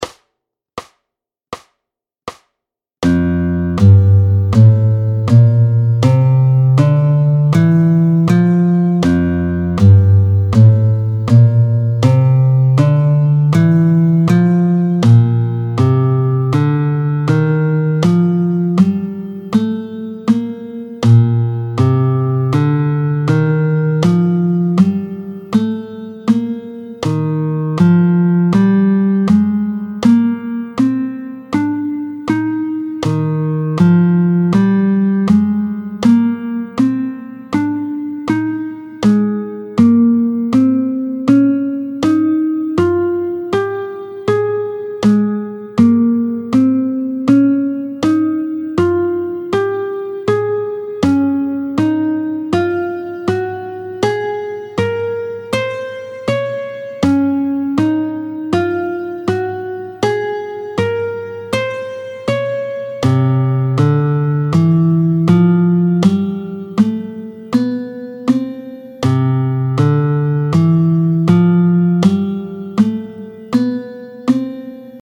Luth
09-06 Les gammes en bémols, Fa, SIb, Mib, Lab, Réb… et la gamme de Do sans altérations